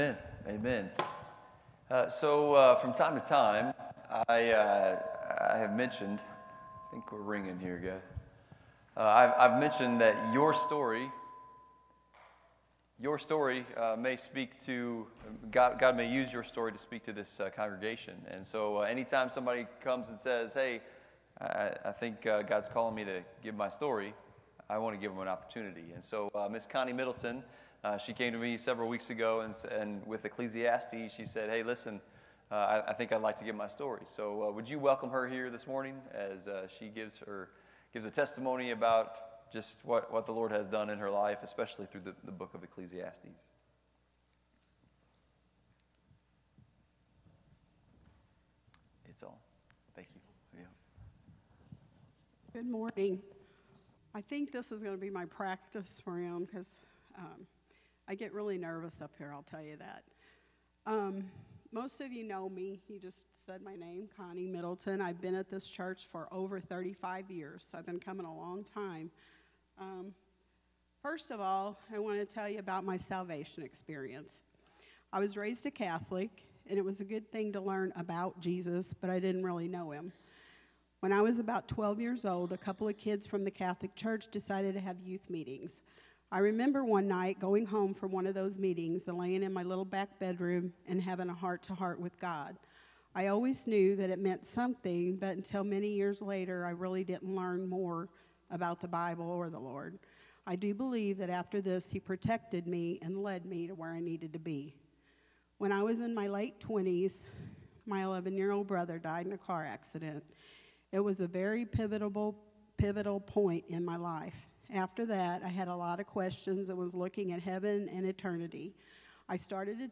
Sermons by FBC Potosi